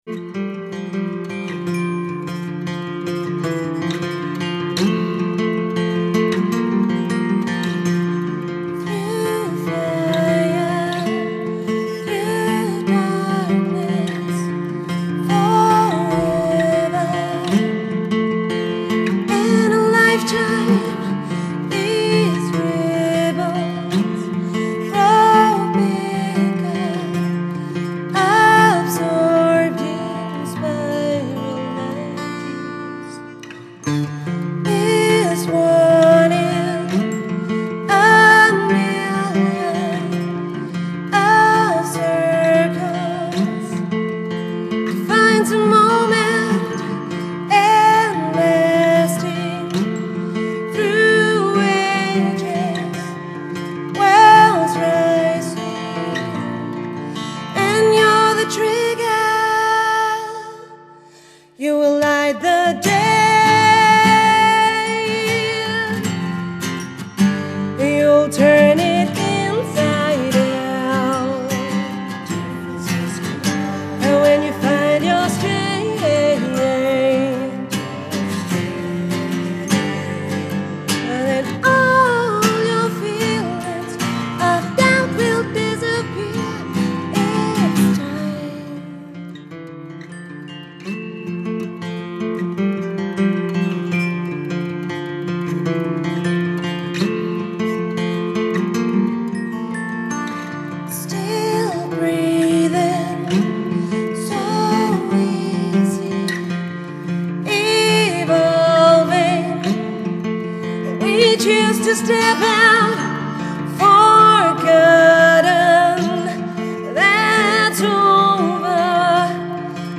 акустическую версию новой песни